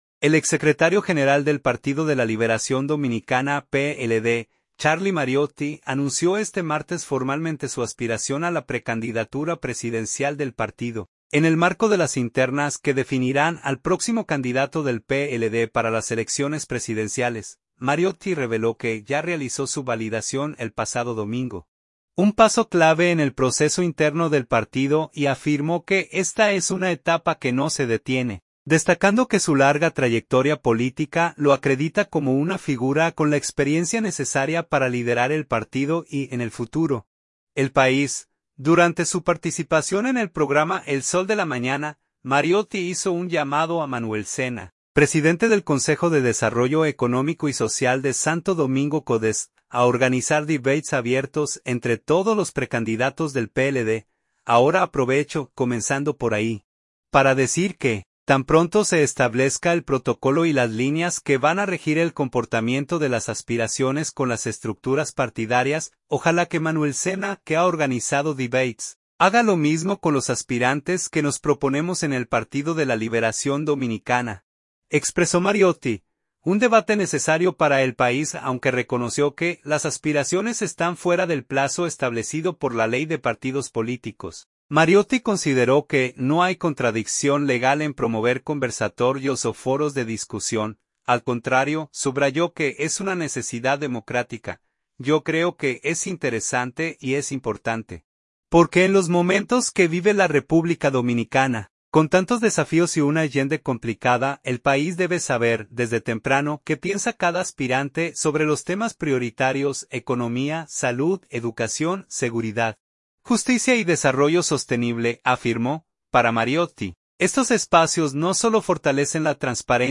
Durante su participación en el programa El Sol de la Mañana